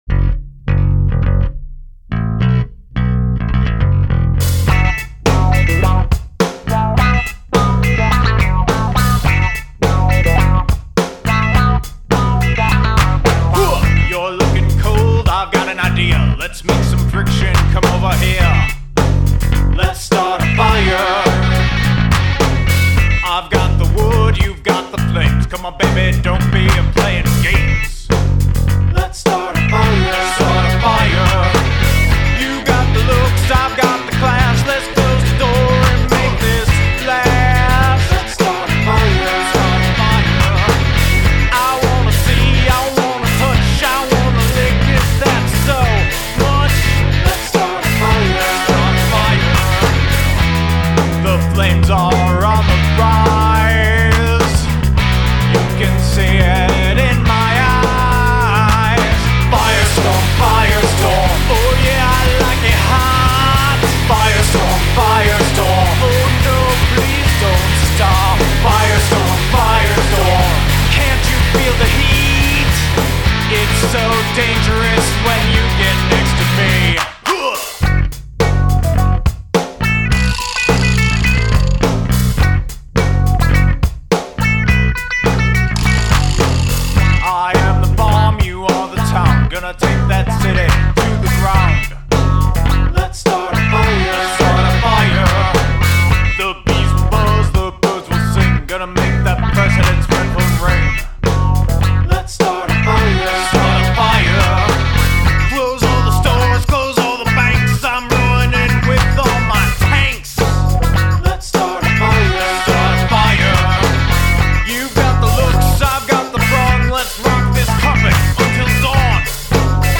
Use of Falsetto